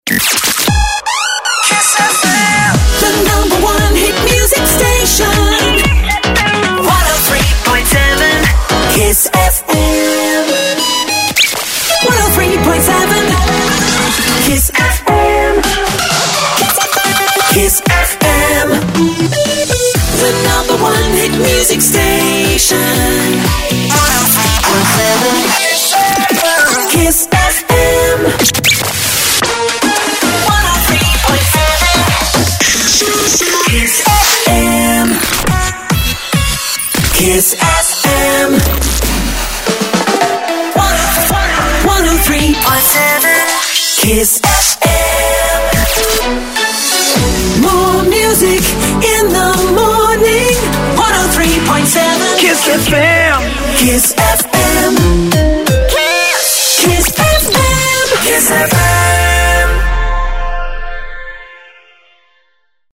cut-through CHR jingles
Instrumentals, Vocals